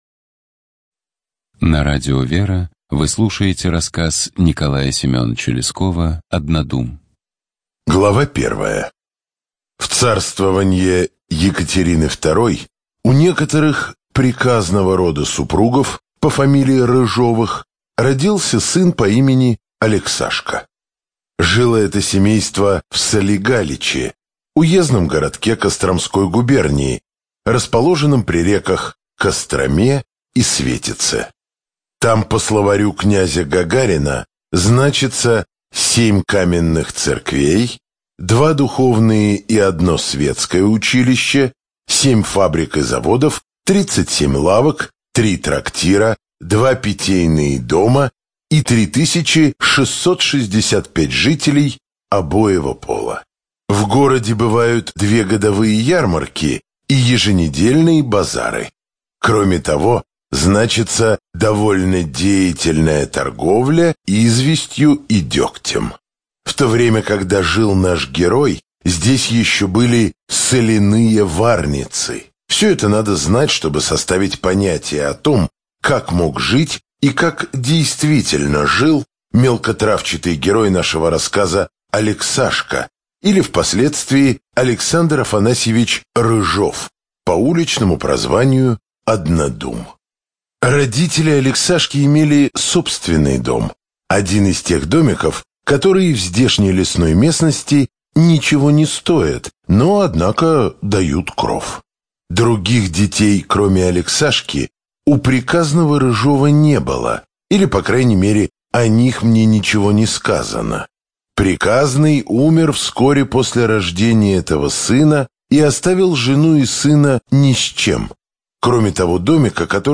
ЖанрКлассическая проза
Студия звукозаписиРадио Вера